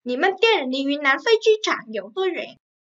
Nǐmen diàn lí YúnNán fēi jī chǎng yǒu duō yuǎn?
Nỉ mân ten lí duýn nán phây chi trảng giẩu tua doẻn ?